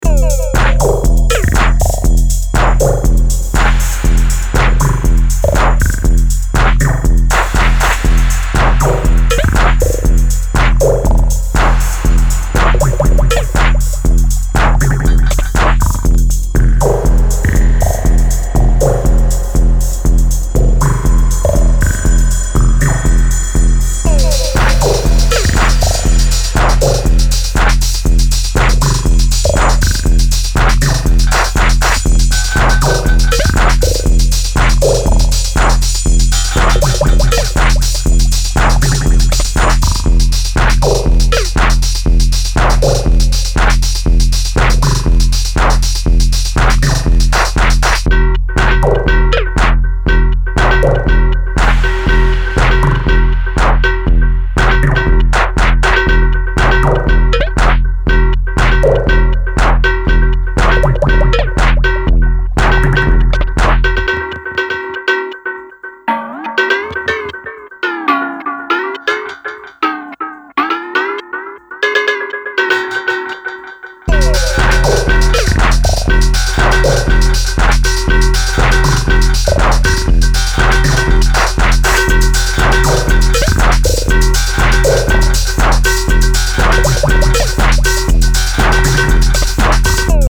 Electronix Techno Wave